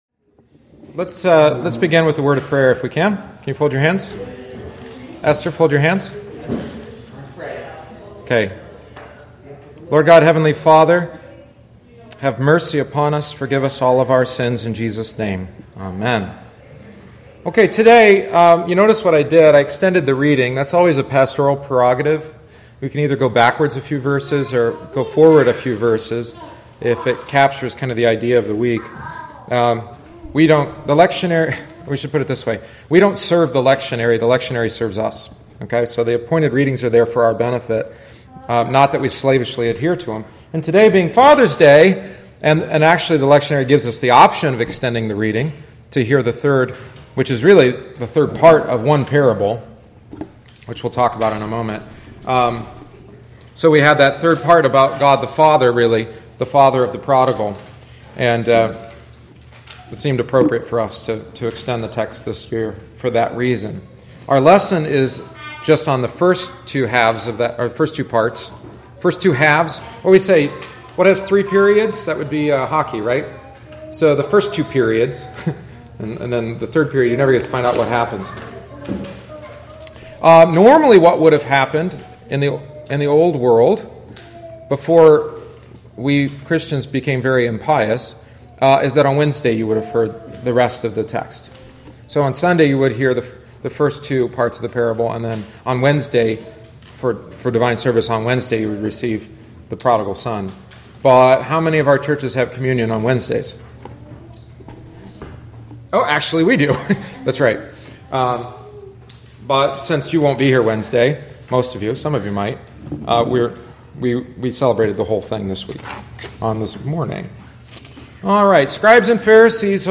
Bible Study for the Sunday of the Lost Sheep, Coin, and Son